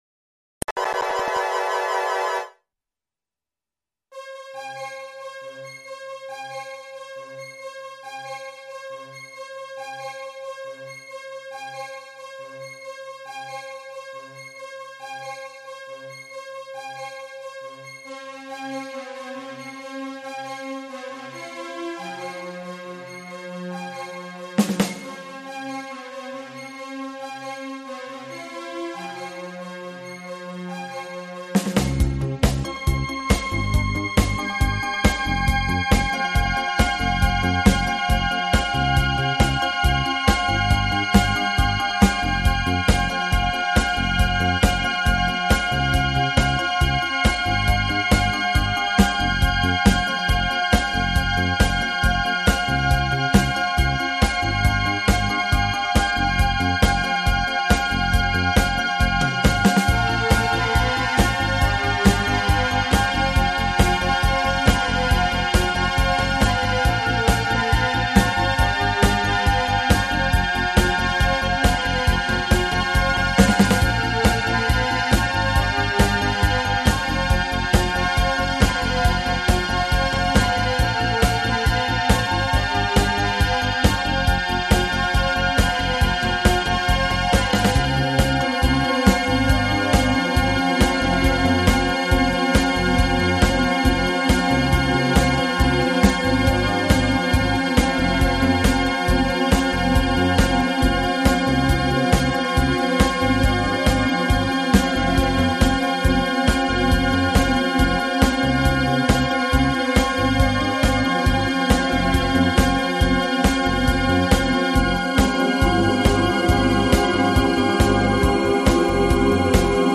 Professional musical transcription (backing track)